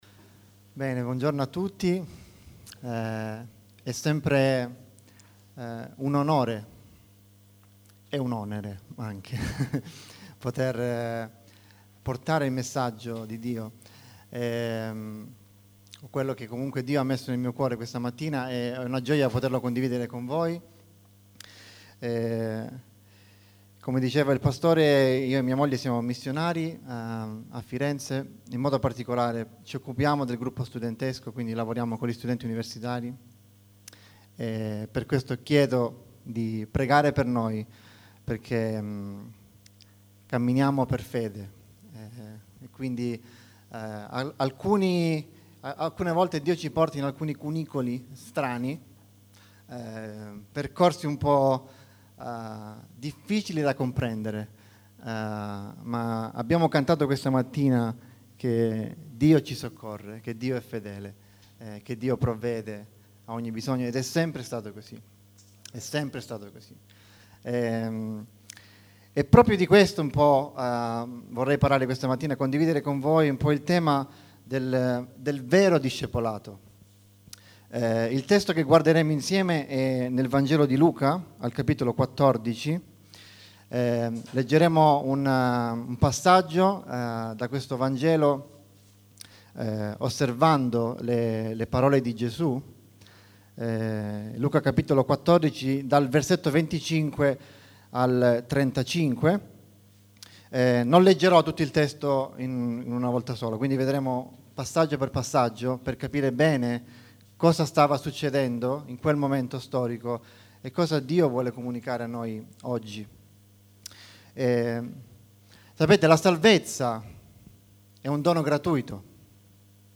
Il dono della critica › Pubblicato in Messaggio domenicale